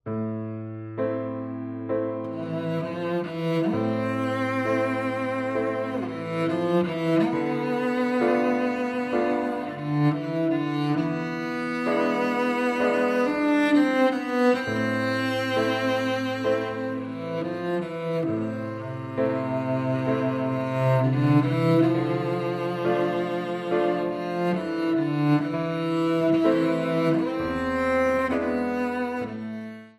Besetzung: Violoncello